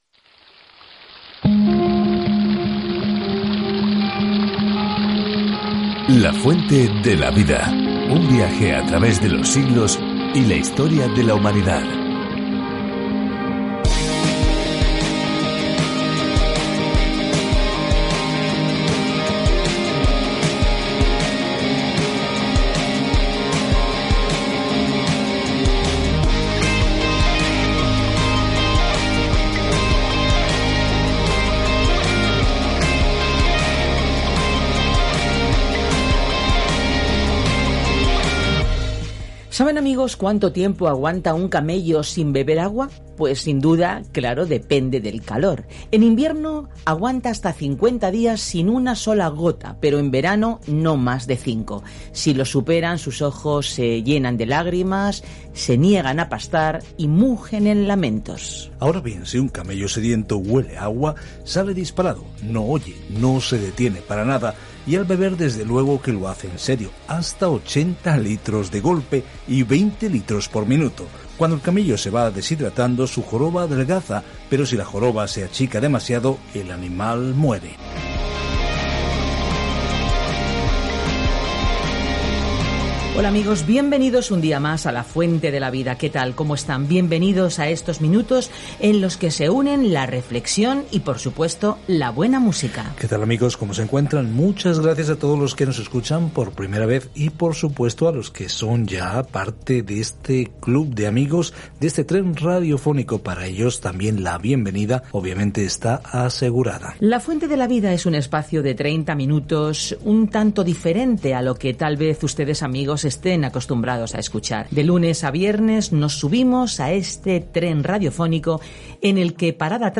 Escritura 1 JUAN 5:6-12 Día 23 Iniciar plan Día 25 Acerca de este Plan No hay término medio en esta primera carta de Juan: o elegimos la luz o las tinieblas, la verdad a la mentira, el amor o el odio; abrazamos uno u otro, tal como creemos o negamos al Señor Jesucristo. Viaja diariamente a través de 1 Juan mientras escuchas el estudio en audio y lees versículos seleccionados de la palabra de Dios.